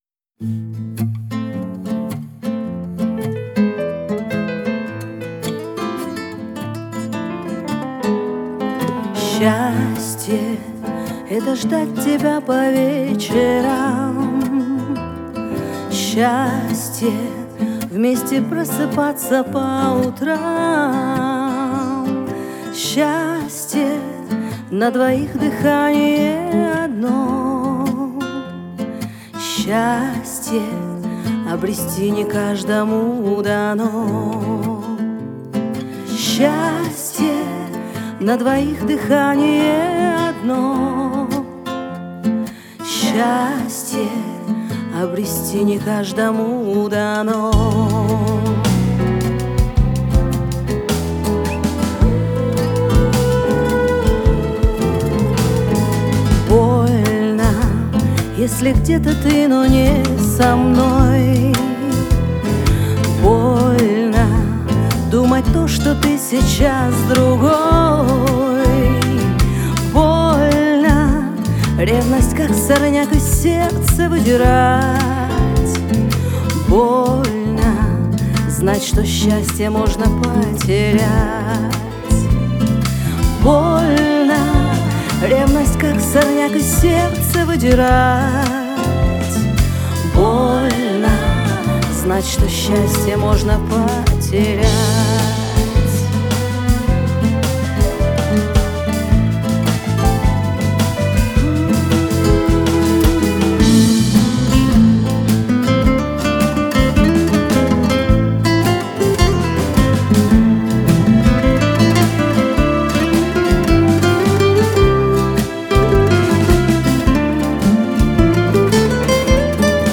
это проникновенная песня в жанре поп